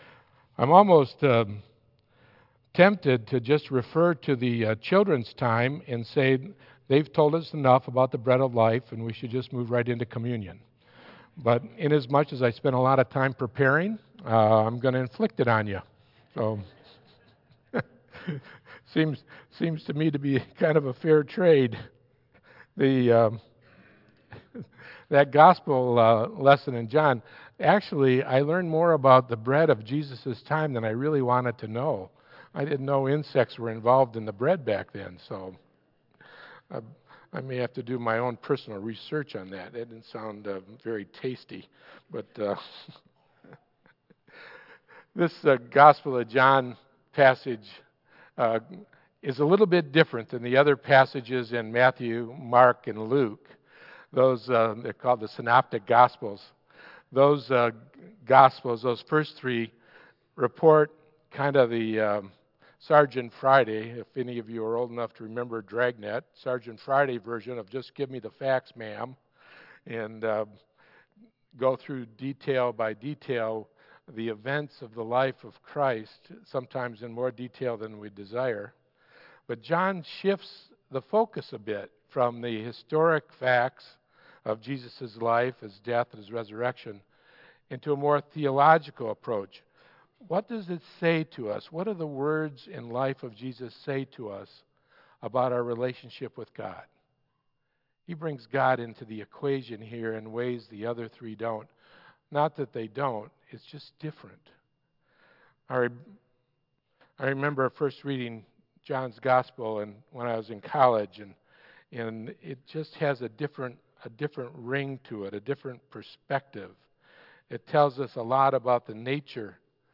This message about Jesus as the bread of life is based on John 6:25-36. Tagged with Central United Methodist Church , Michigan , Sermon , Waterford , Worship Audio (MP3) 8 MB Previous The Attitude of Compassion Next The Attitude of Humility